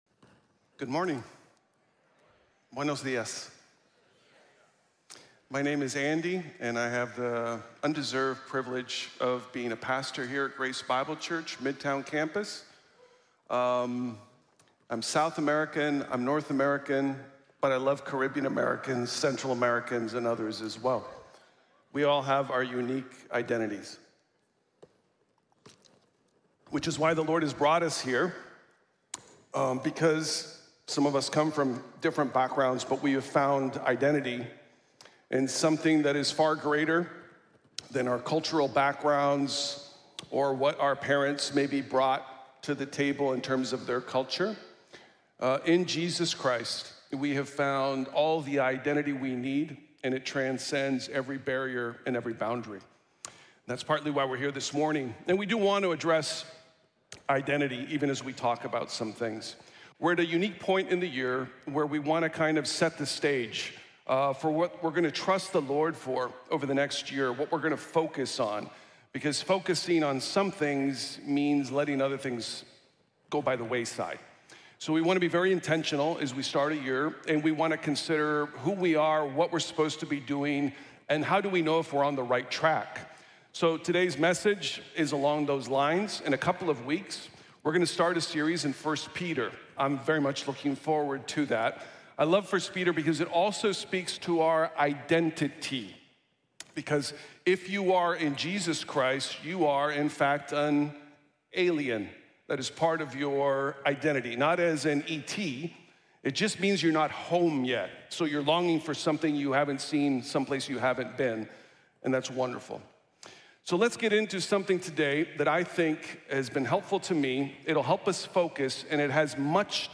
Disciples Make Disciples | Sermon | Grace Bible Church